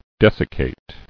[des·ic·cate]